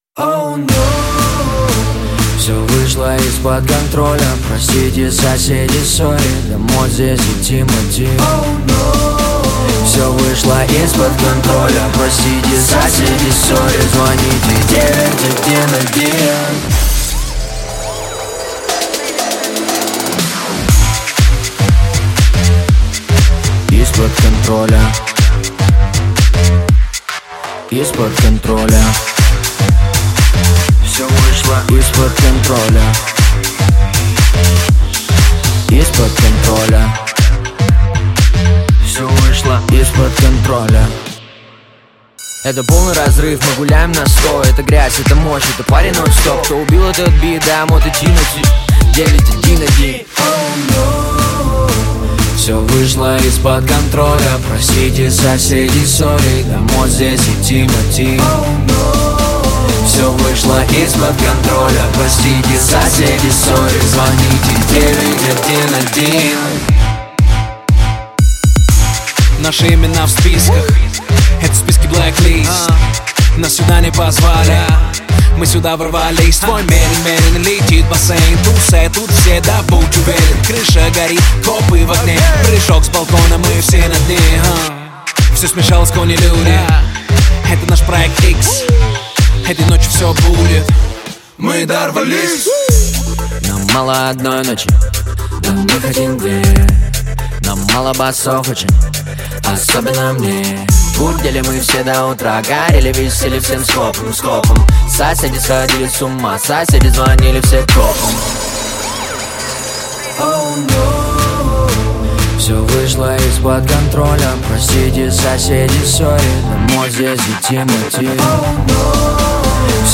Жанр: Жанры / Электроника